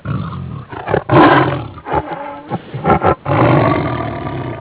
Bobcat
Bobcat.wav